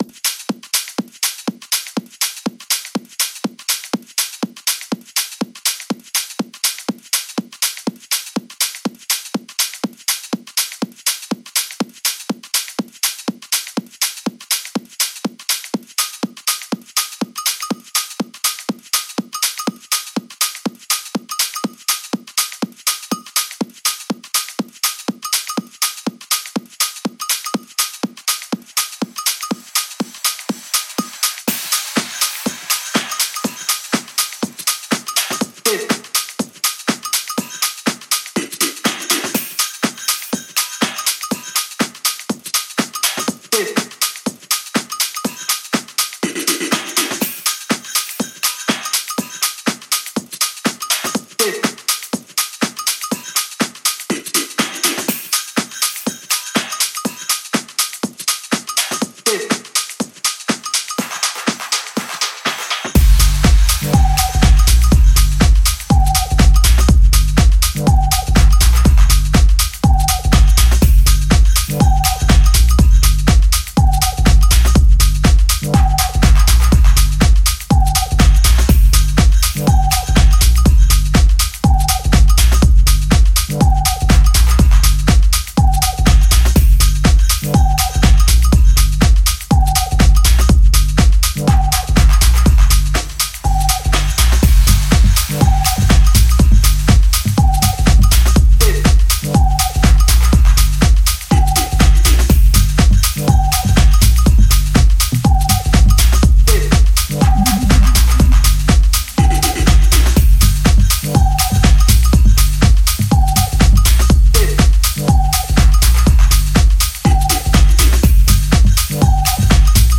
groovy techie gem with great bassline
House